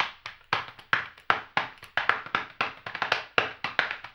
HAMBONE 18-L.wav